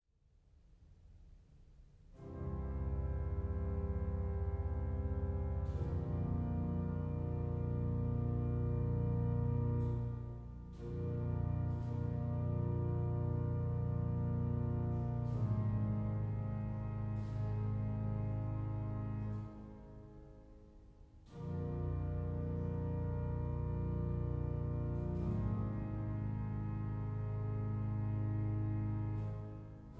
Bariton
Orgel